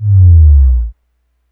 808_drum_kit / classic 808
HBA1 08 bass01.wav